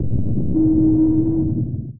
sonarTailSuitCloseShuttle1.ogg